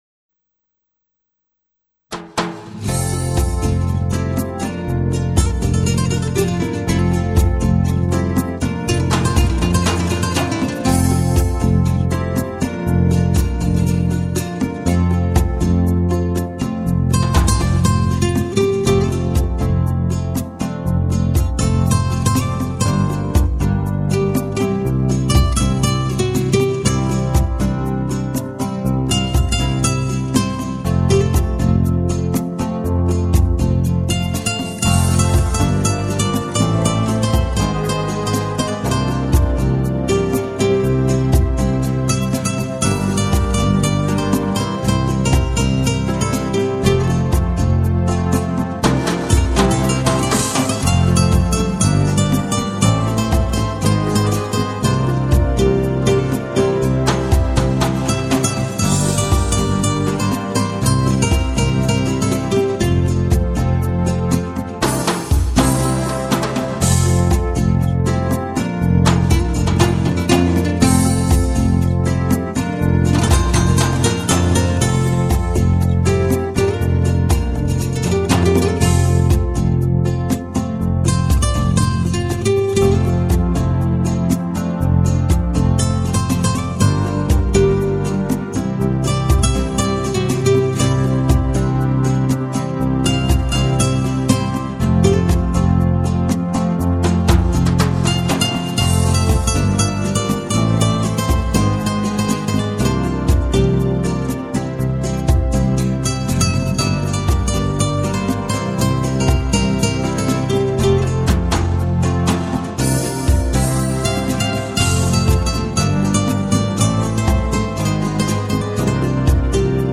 0242-吉他名曲苏门特乌娜.mp3